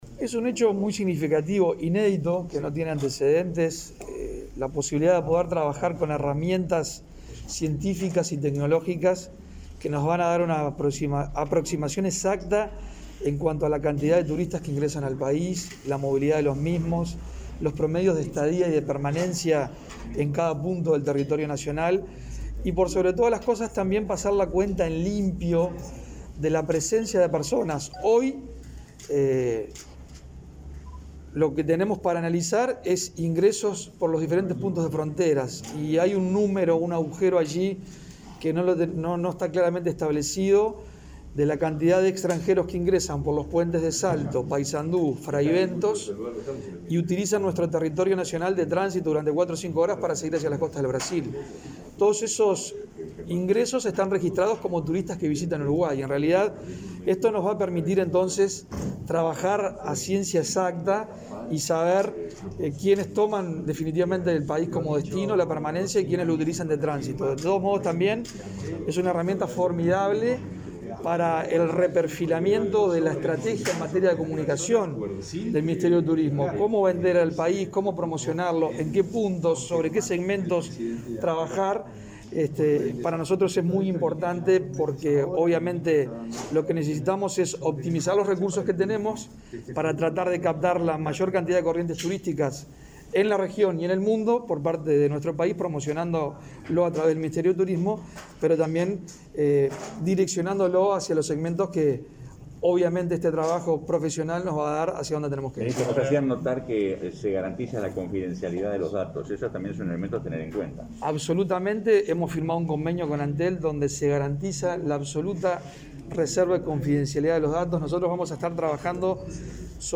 Declaraciones del ministro de Turismo, Germán Cardoso